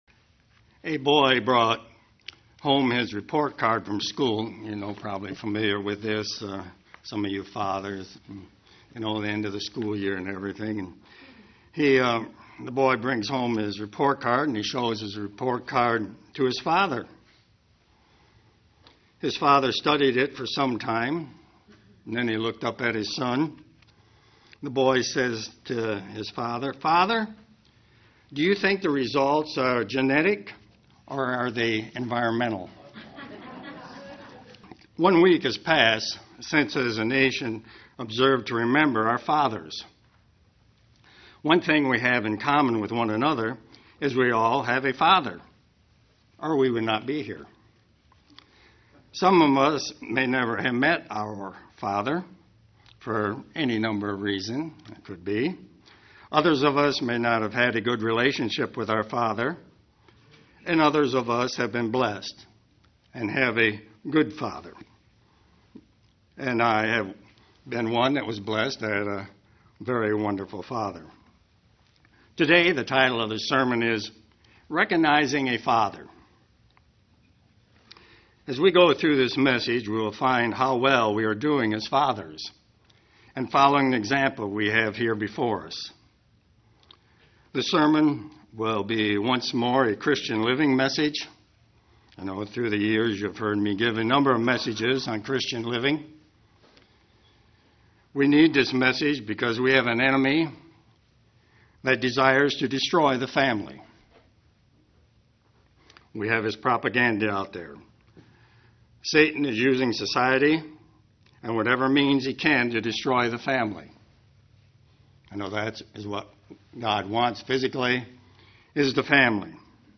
Given in Ann Arbor, MI
UCG Sermon Studying the bible?